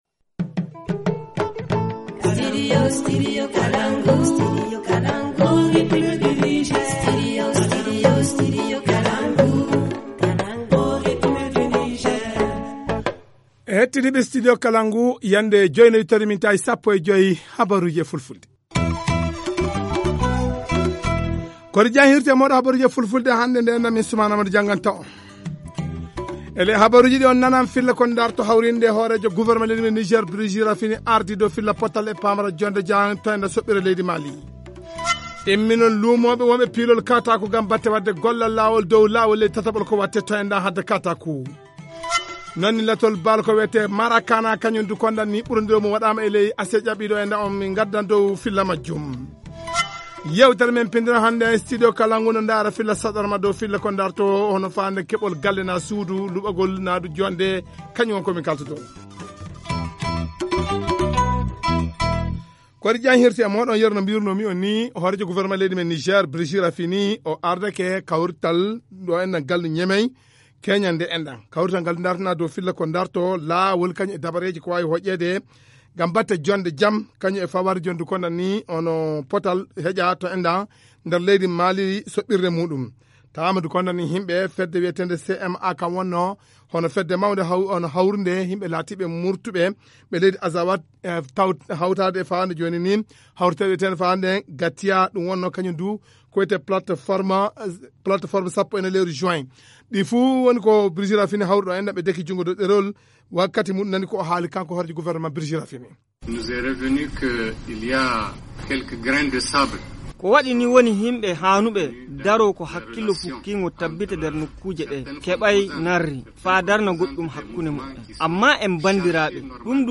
Journal en français.